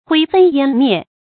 灰飞烟灭 huī fēi yān miè 成语解释 灰也飞散了；烟也消失了。
成语繁体 灰飛煙滅 成语简拼 hfym 成语注音 ㄏㄨㄟ ㄈㄟ ㄧㄢ ㄇㄧㄝ ˋ 常用程度 常用成语 感情色彩 中性成语 成语用法 联合式；作谓语；比喻人亡或事物迅速消失 成语结构 联合式成语 产生年代 古代成语 成语正音 灭，不能读作“mō”。